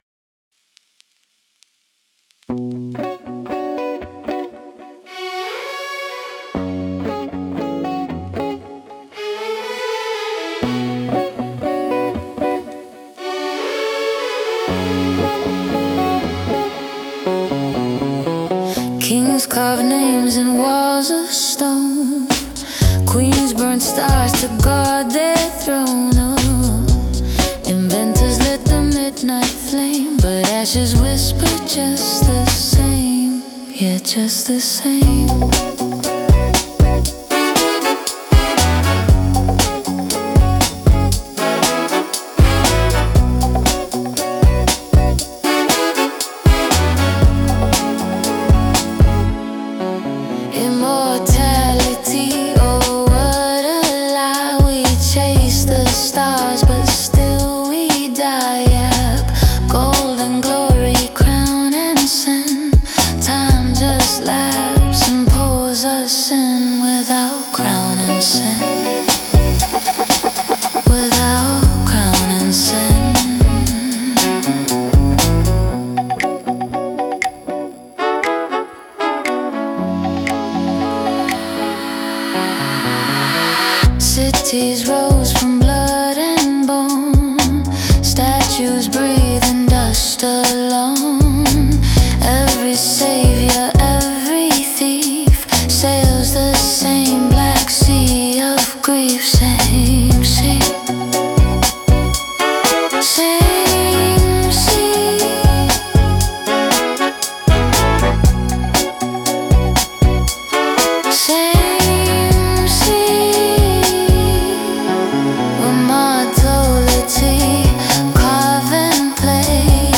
“Dust of Immortals” is a poetic, cinematic funk-rap song about humanity’s eternal obsession with immortality — from ancient kings and monuments to modern fame and power. It mixes spoken word, whispered poetry, and acid rap over a deep disco-funk groove and cinematic textures. The song feels both groovy and philosophical — a dance between vanity and eternity.